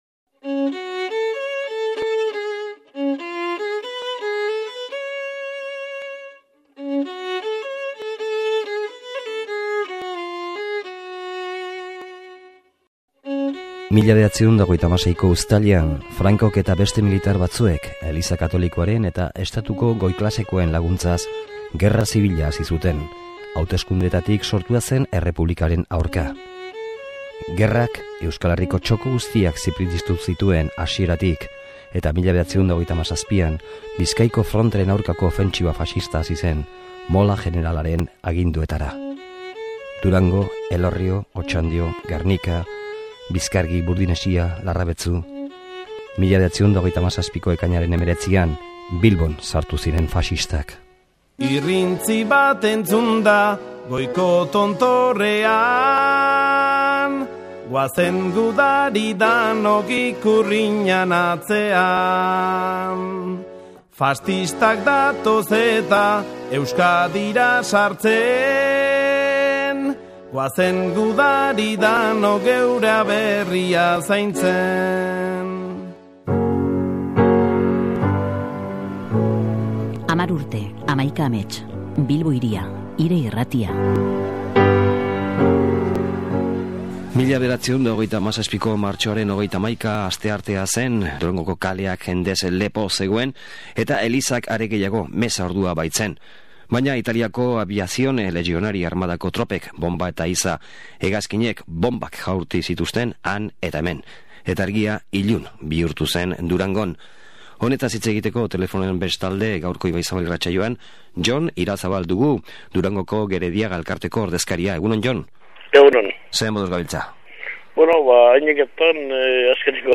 elkarrizketatuez gain, jakina.